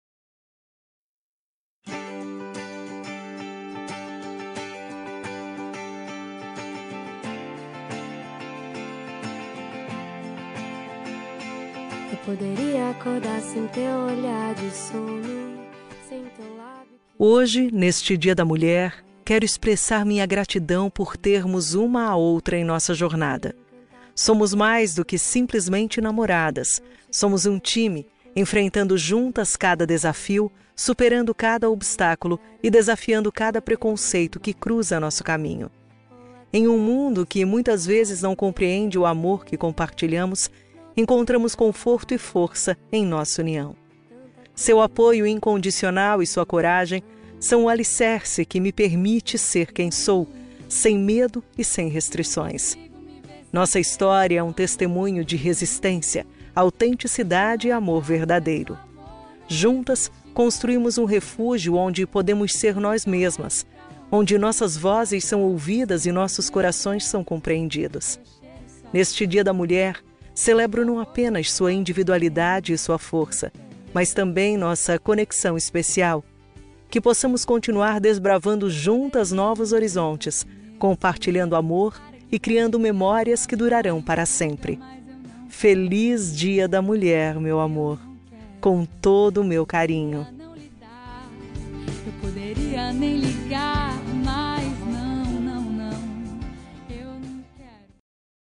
Dia da Mulher – Namorada – Feminina – Cód: 690606